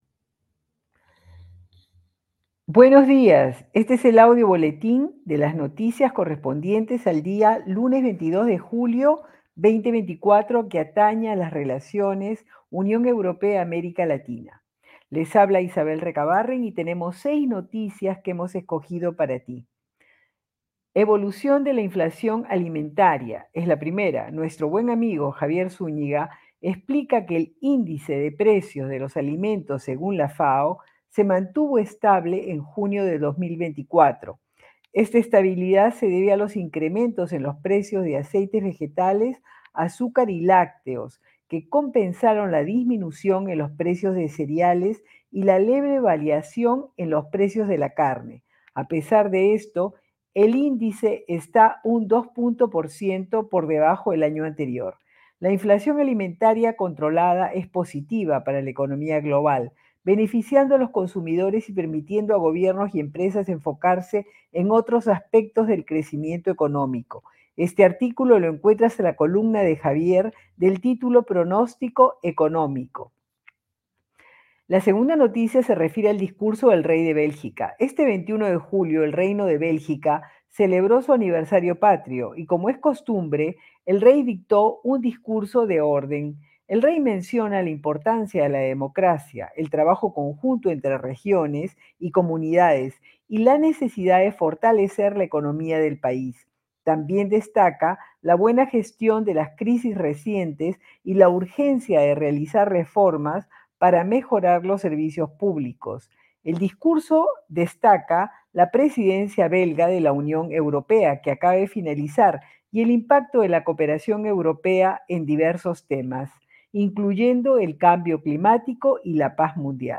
Audio Boletín de Noticias del Lunes 22 de Julio 2024